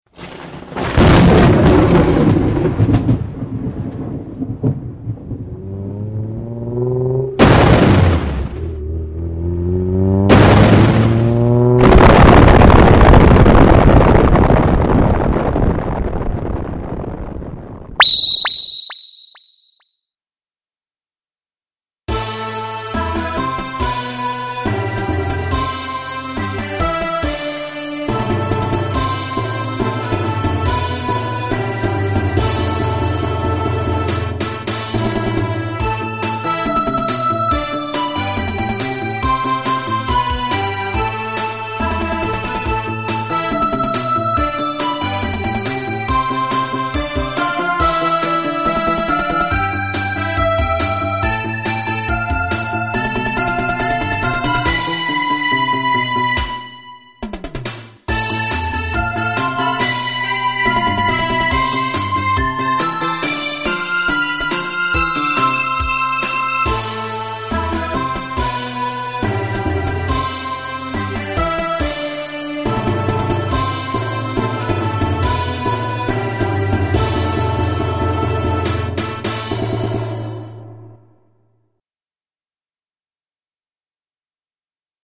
２　オープニングテーマ「